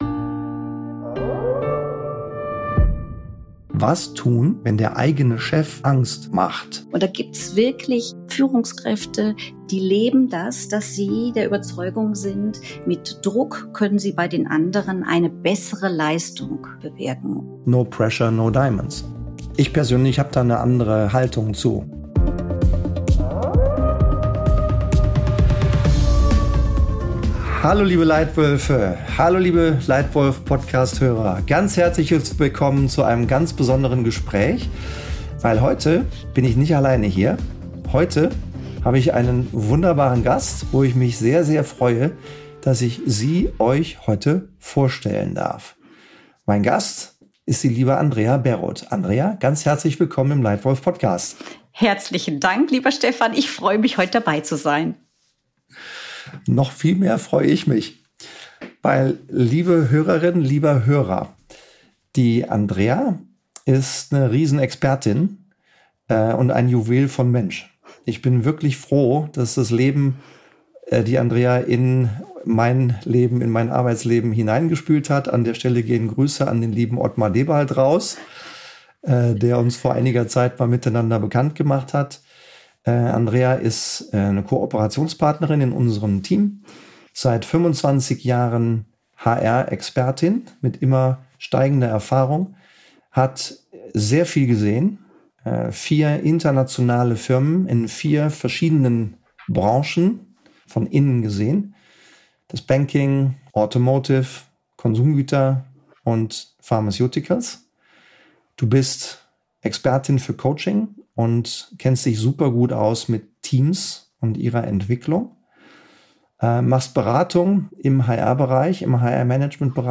Ein ehrliches Gespräch über Mut, Haltung und die Kunst, Angst durch Vertrauen zu ersetzen.